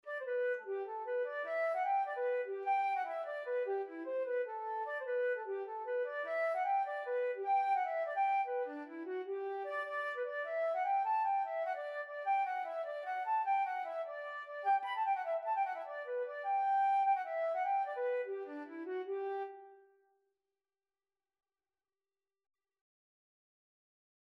(Irish Folk Song) Flute version
6/8 (View more 6/8 Music)
G major (Sounding Pitch) (View more G major Music for Flute )
Instrument:
Traditional (View more Traditional Flute Music)